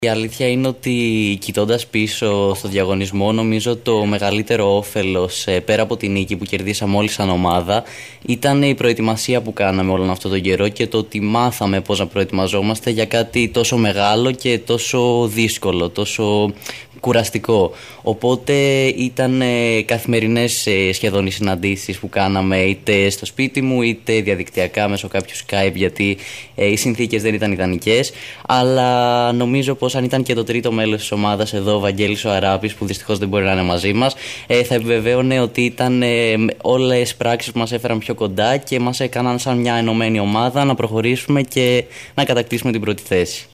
φιλοξενήθηκαν σήμερα στην ΕΡΤ Κομοτηνής και στην εκπομπή «Καθημερινές Ιστορίες»